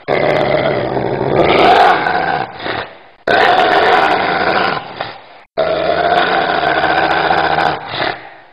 دانلود صدای سگ وحشتناک و وحشی از ساعد نیوز با لینک مستقیم و کیفیت بالا
جلوه های صوتی